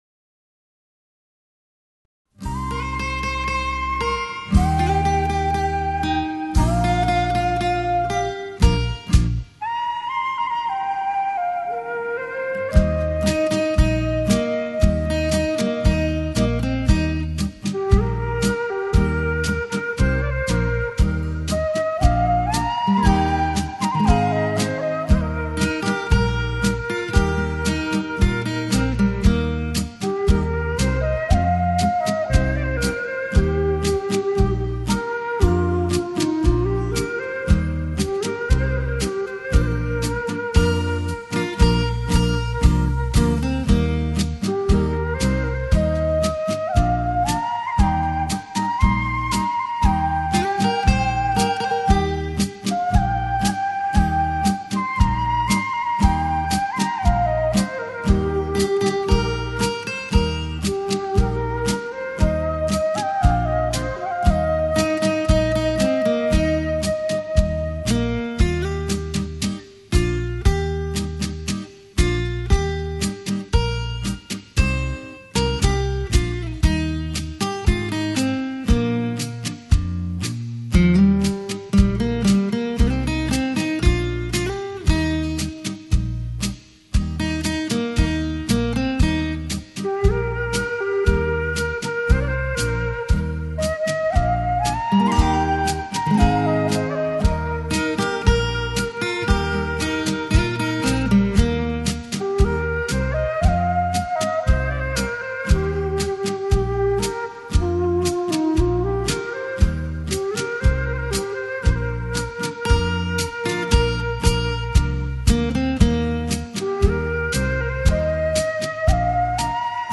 尺八